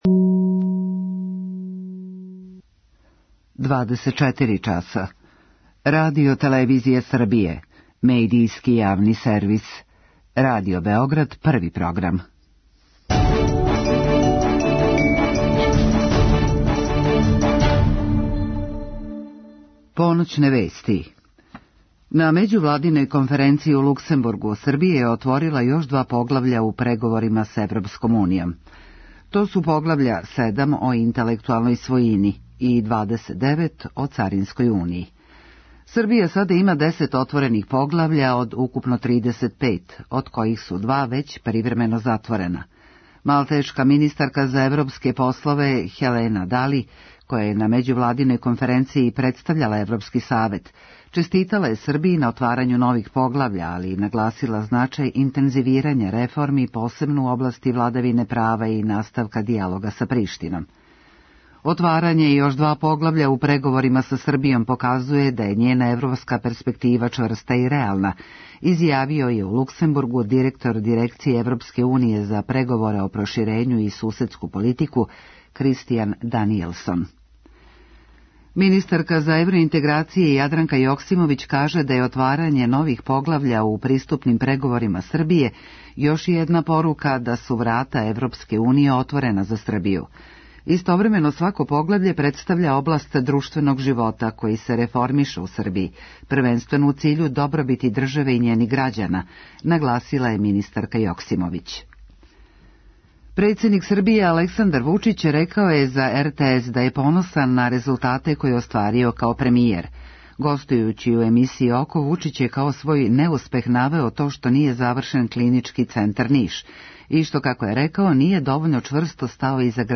У другом сату емисије, наше слушатељке и слушаоци имају прилику да се директно укључе са коментарима, предлозима и проблемима који се односе на психолошки свет, међу свим другим световима.